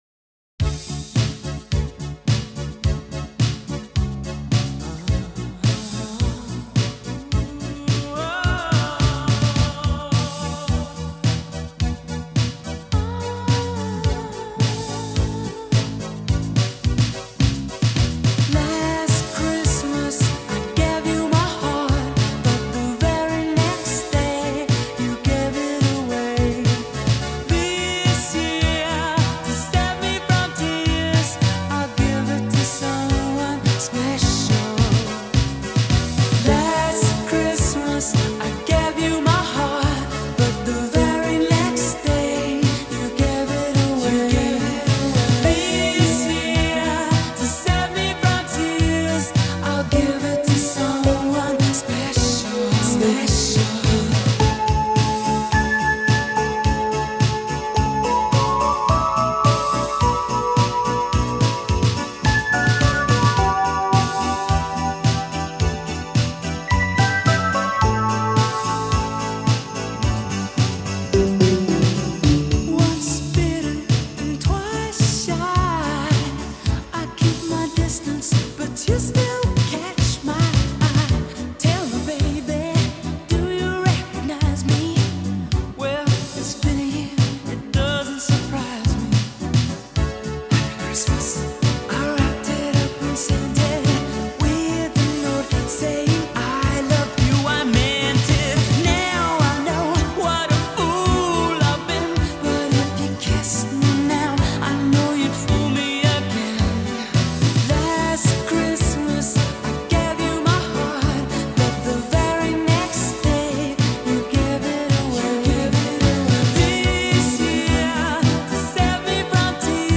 ریتم آرام